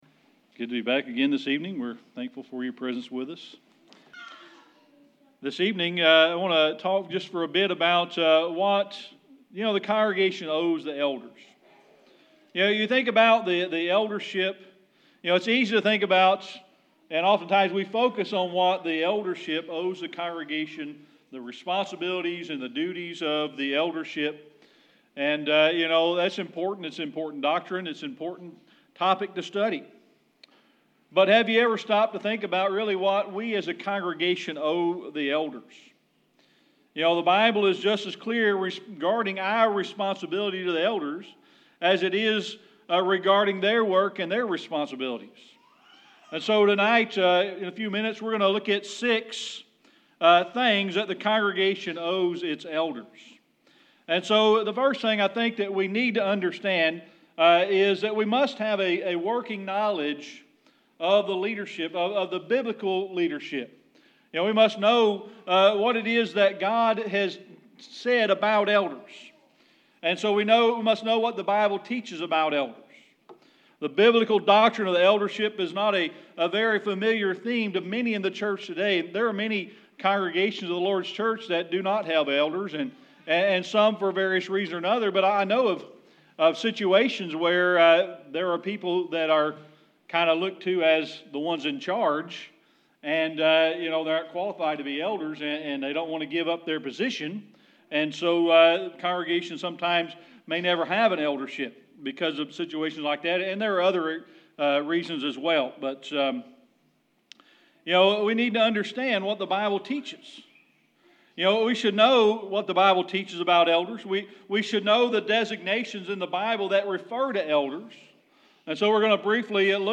Series: Sermon Archives
1 Thessalonians 5:12-13 Service Type: Sunday Evening Worship I want to talk for a bit about what a congregation owes its elders.